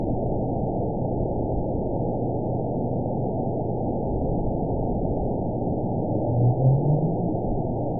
event 914415 date 05/07/22 time 11:17:18 GMT (3 years ago) score 9.06 location TSS-AB01 detected by nrw target species NRW annotations +NRW Spectrogram: Frequency (kHz) vs. Time (s) audio not available .wav